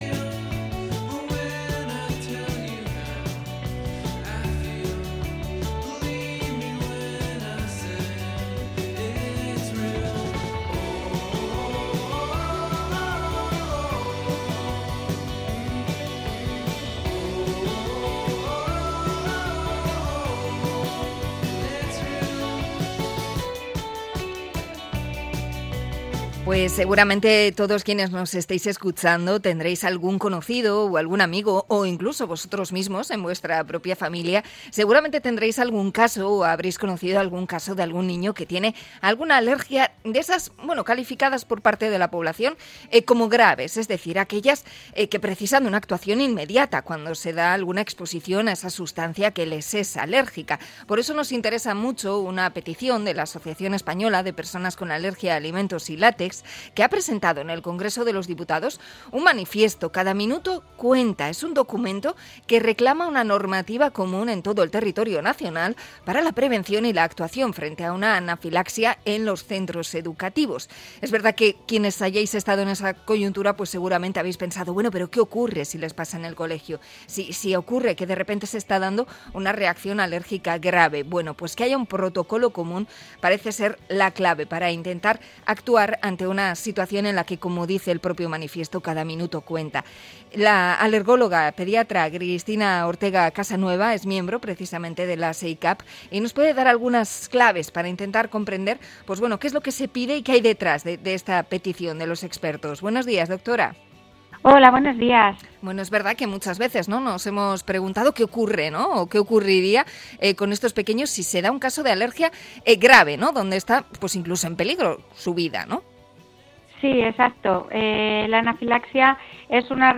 Entrevista a alergóloga infantil por el manifiesto que han firmado